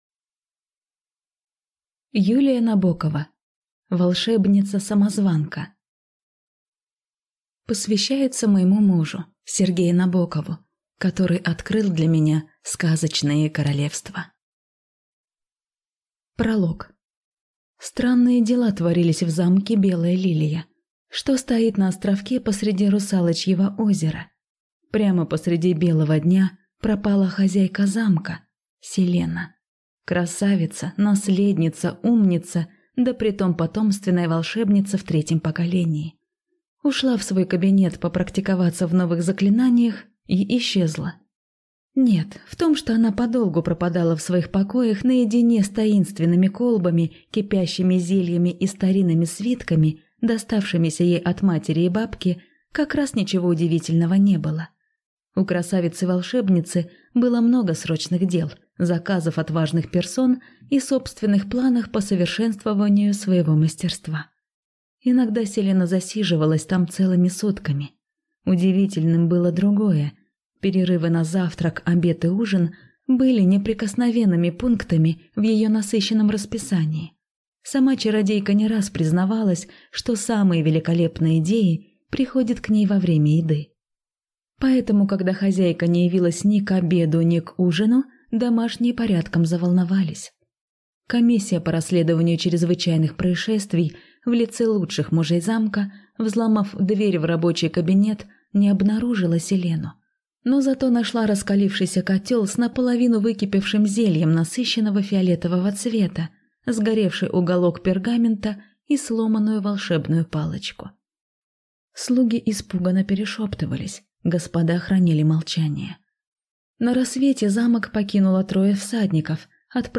Аудиокнига Волшебница-самозванка | Библиотека аудиокниг